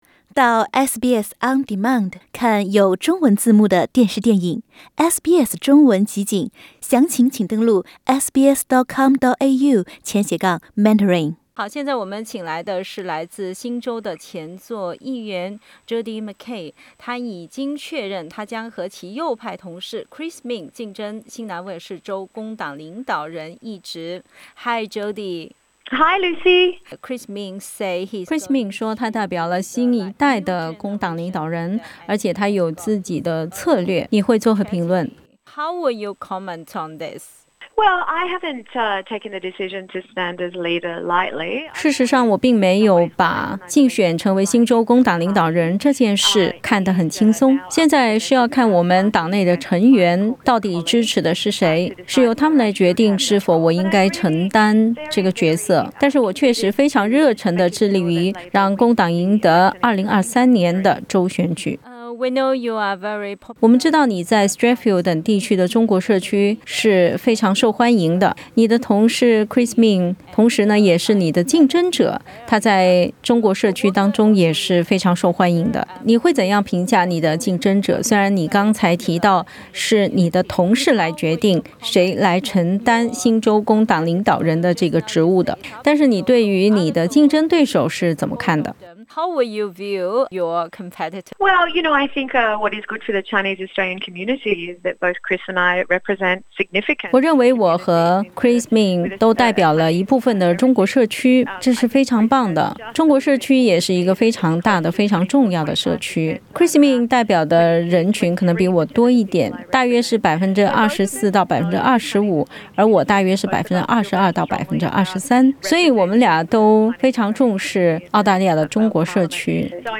新州Strathfield州议员Jodi McKay接受SBS 普通话采访，她说，如果当选，华人社区将会受益于工党的政策，新州也一如既往地重视中澳贸易的价值。 Jodi McKay 承认，工党在新州选举中连连失利，令其支持者和工党成员感到失望。